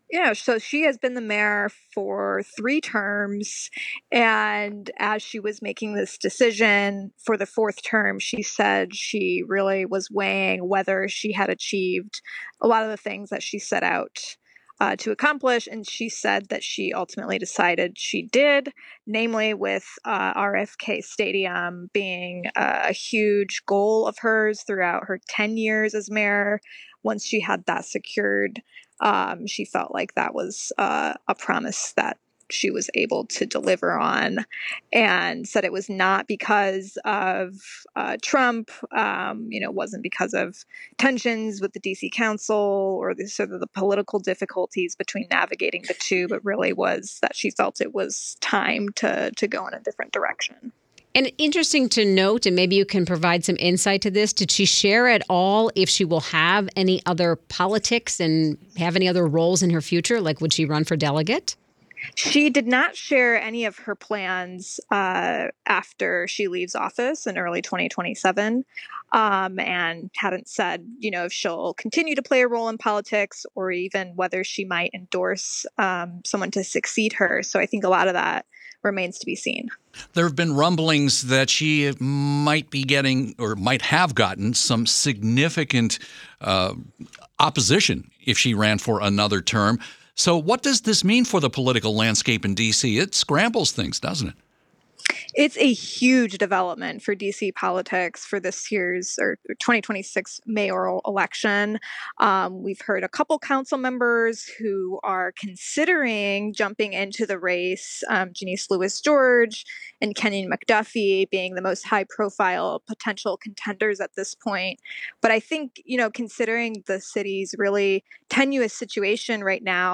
Why did Bowser decide against reelection bid? Washington Post reporter
WTOP anchors